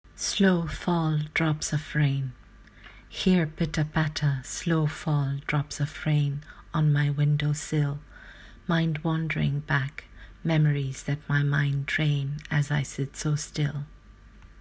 Reading of the poem:
slow-fall-drops-of-rain.m4a